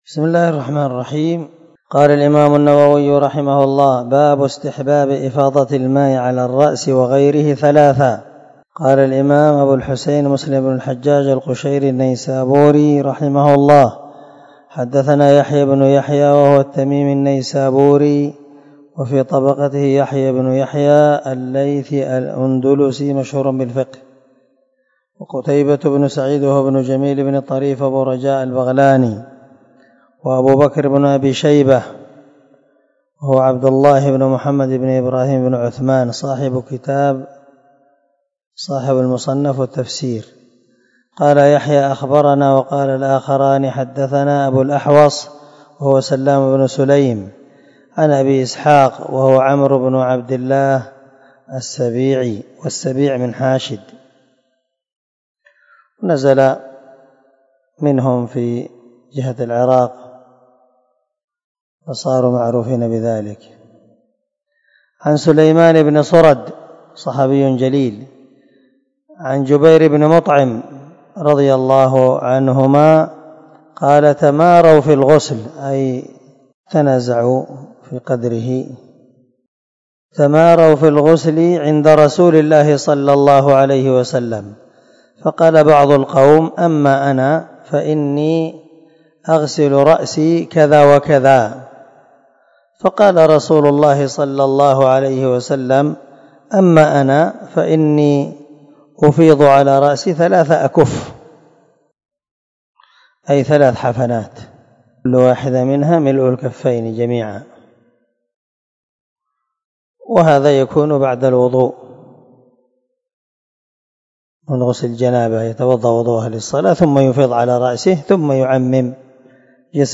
سلسلة_الدروس_العلمية
✒ دار الحديث- المَحاوِلة- الصبيحة.